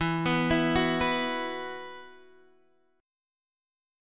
Esus2 Chord